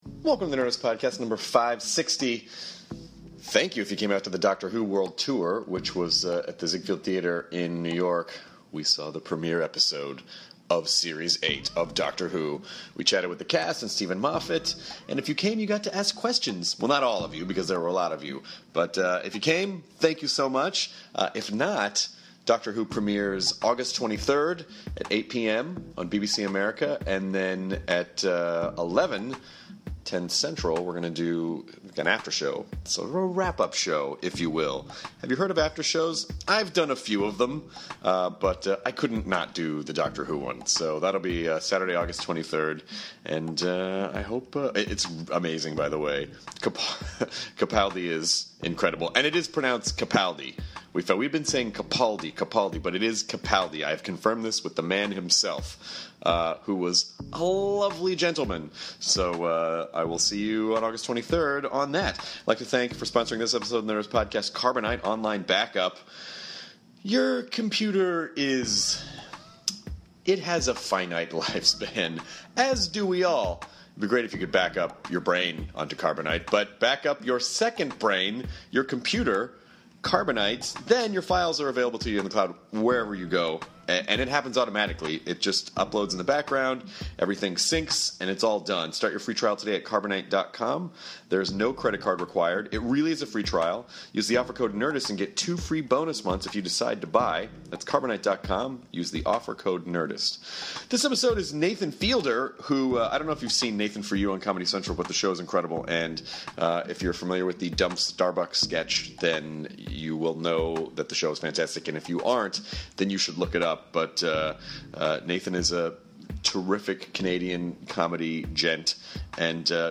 Nathan Fielder chats with Chris about getting his start creating videos in Canada, choosing people and businesses for his show Nathan For You, whether anyone is still implementing his ideas for their business and how the idea of Dumb Starbucks came alive!